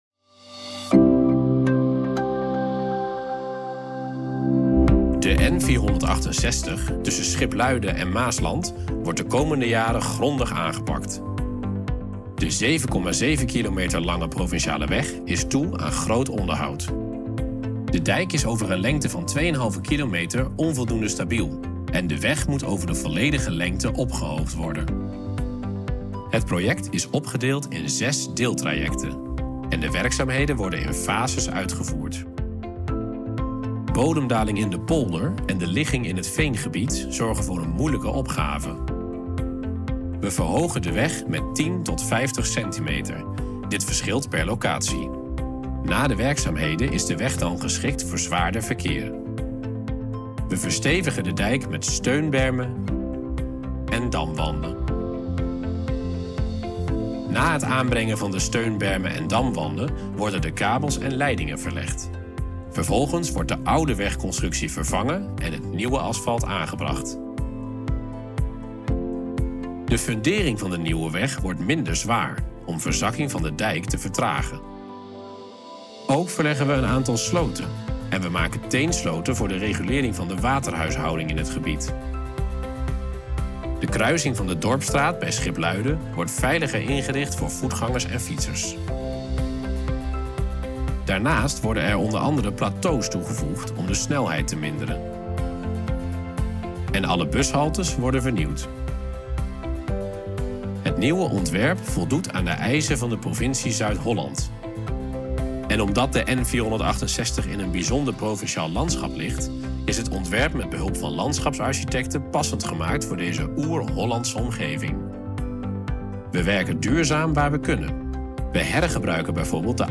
n468_animatie_v3_audio_voice_music_.mp3